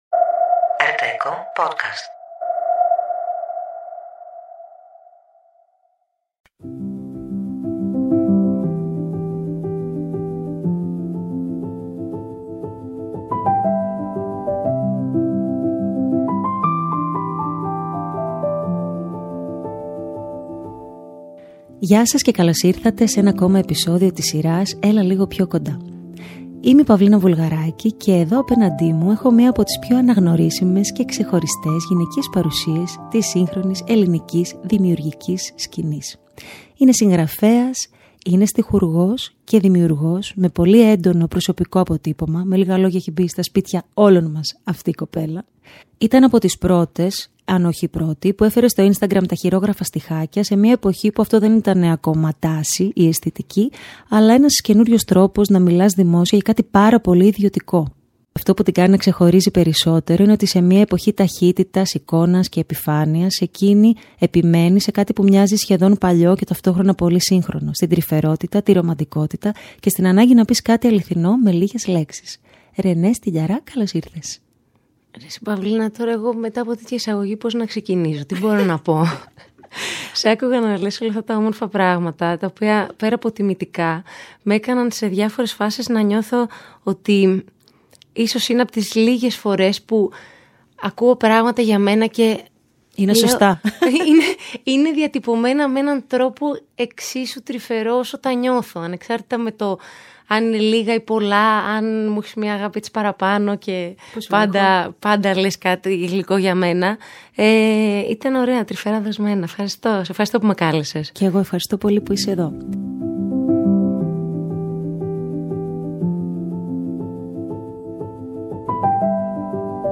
μια σειρά αληθινών συνομιλιών
ενώ στο τέλος κάθε επεισοδίου ακούγεται ένα νέο κομμάτι, γραμμένο από την ίδια ειδικά για τη σειρά.